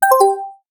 disengage.wav